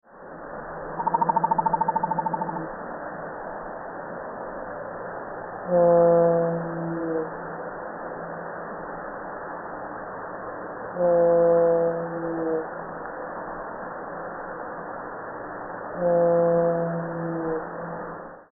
blue-whale-2.mp3